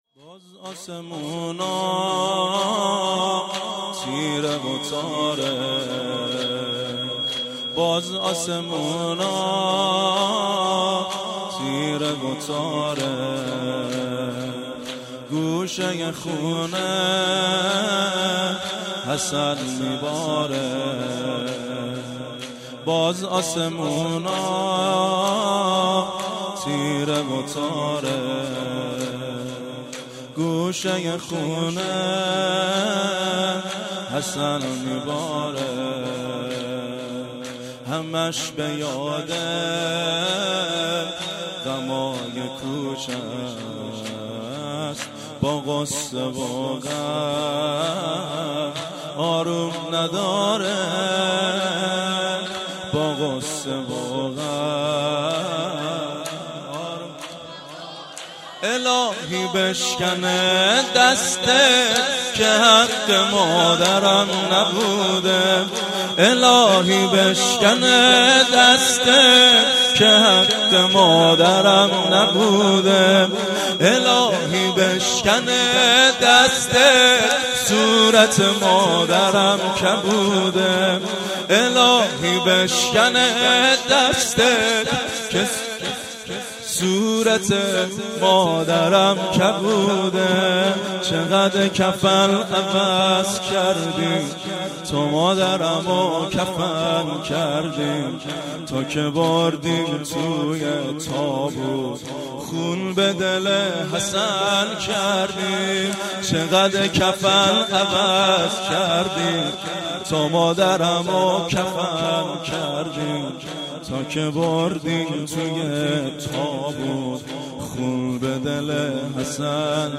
شب دوم ویژه برنامه فاطمیه دوم ۱۴۳۹